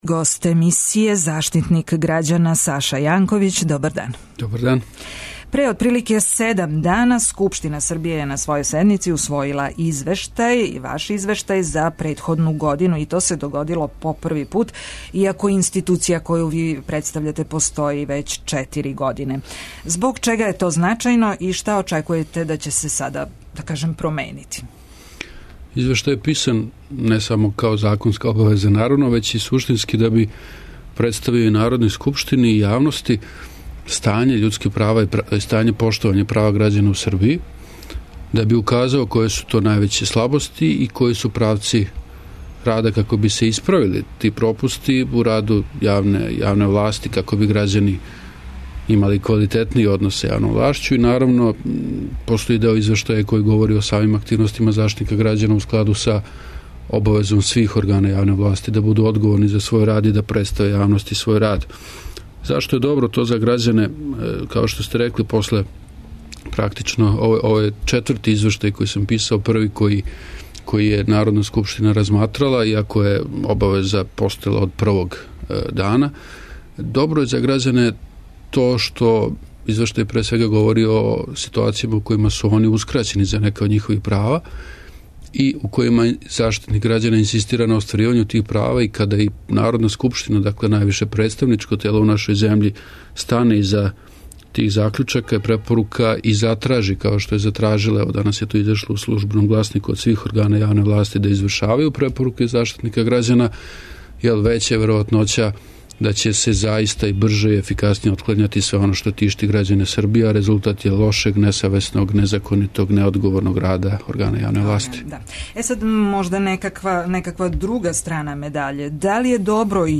Гост емисије је заштитник грађана, Саша Јанковић. Пре неколико дана Скупштина Србије усвојила је, по први пут, извештај о раду омбудсмана за претходну годину.